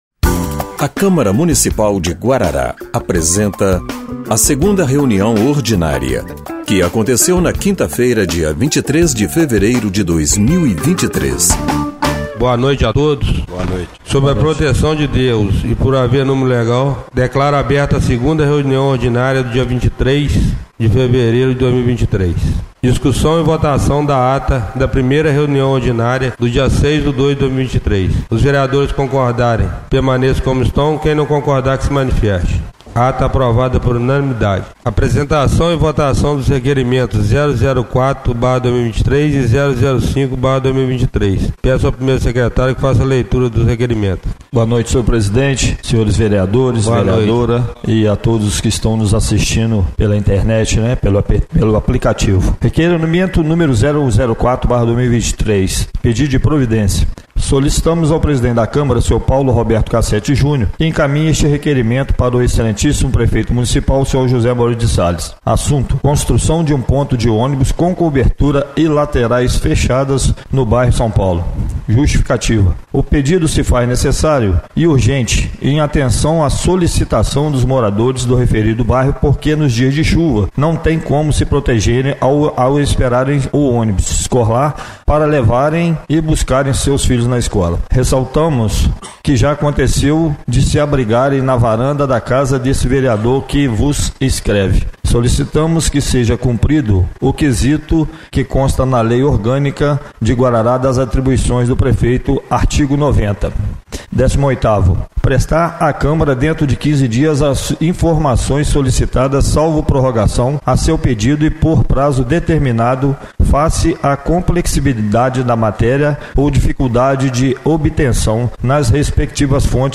2ª Reunião Ordinária de 23/02/2023